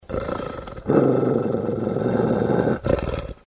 doggrowl.mp3